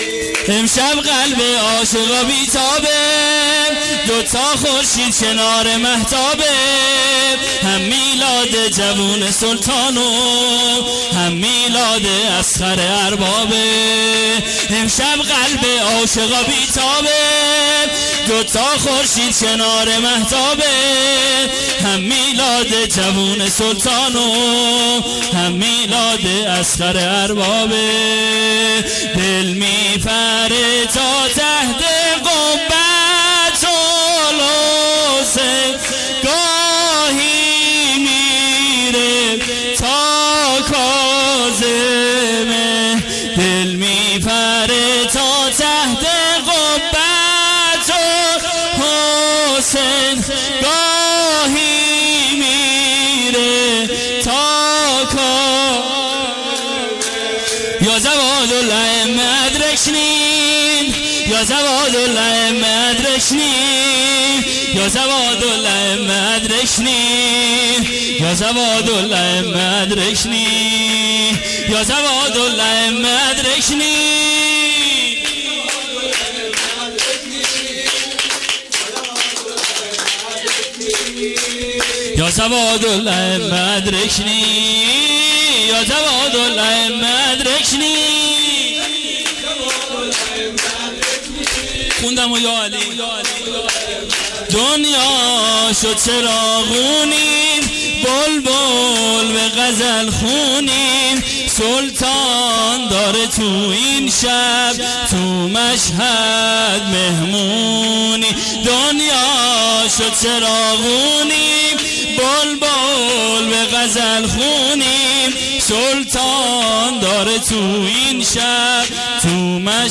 قم جشن میلاد امام جواد(ع) و حضرت علی اصغر (ع)99 اشتراک برای ارسال نظر وارد شوید و یا ثبت نام کنید .